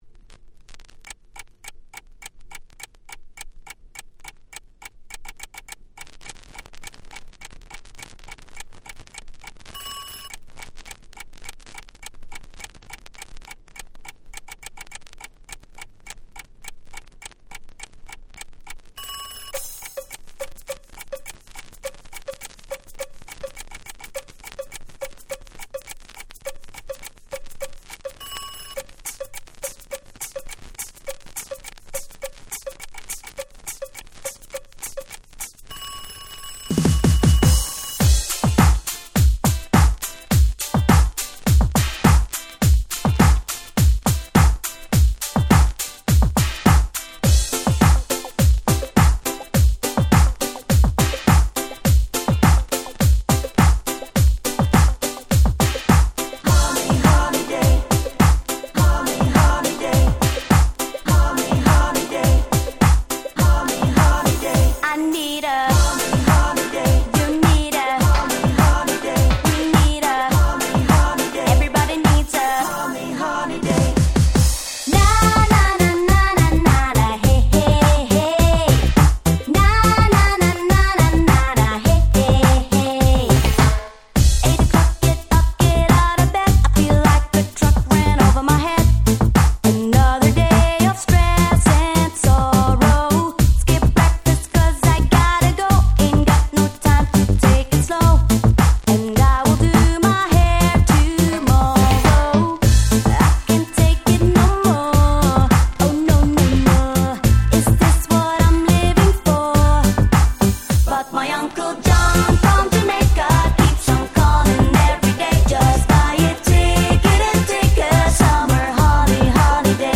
00' Super Hit Dance Pop !!
ラガポップ Ragga Pop
キャッチー系 R&B